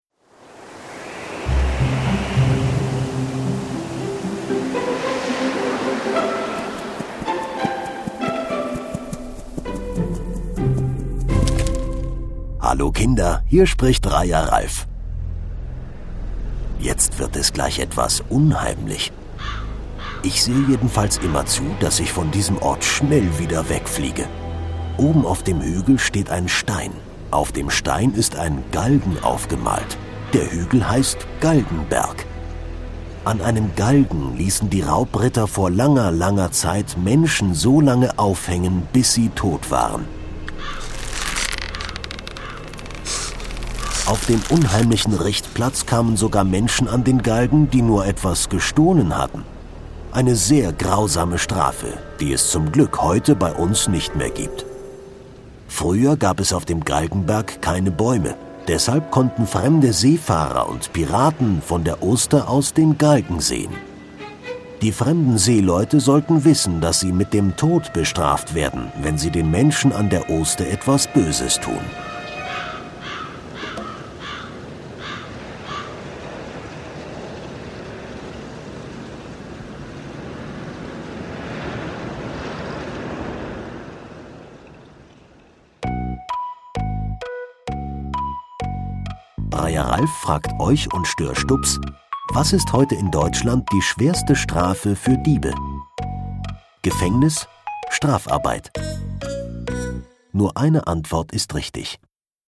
Galgenberg - Kinder-Audio-Guide Oste-Natur-Navi